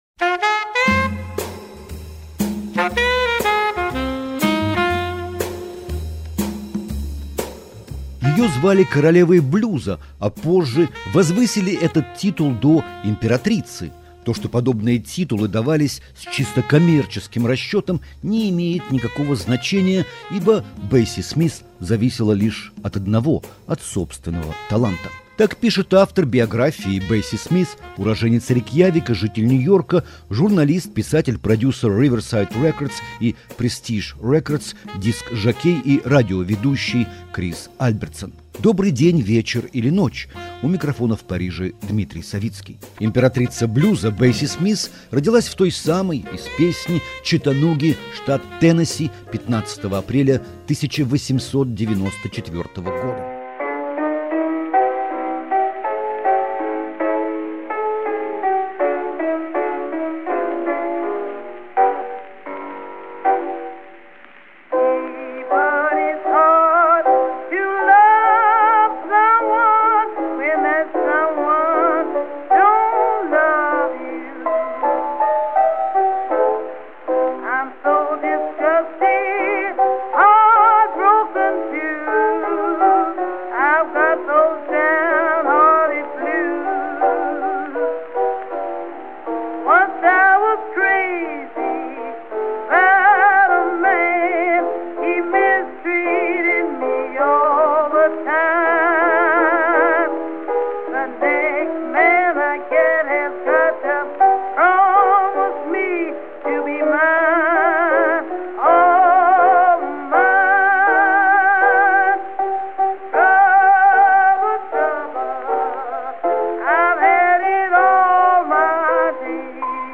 живой эфир